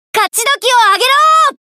ボイスセレクションと川神通信Ｑ＆Ａです